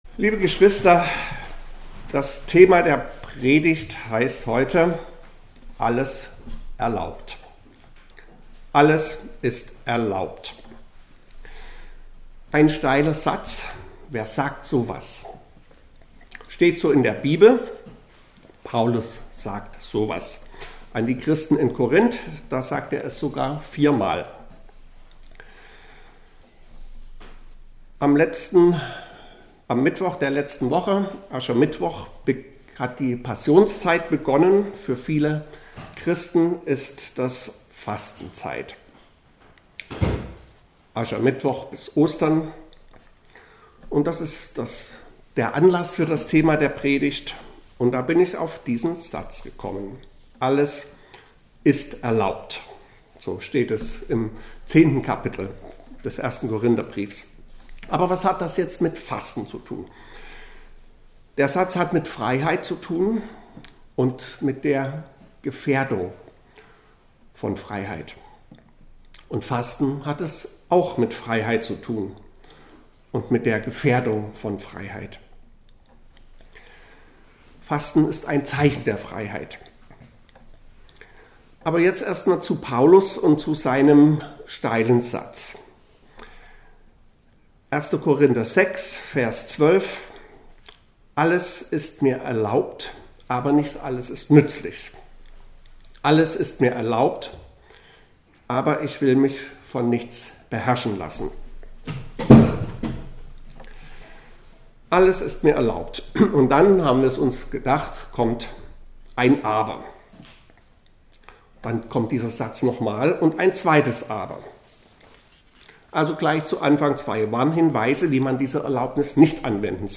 Predigten - Evangelisch-Freikirchliche Gemeinde Berlin Pankow (Niederschönhausen)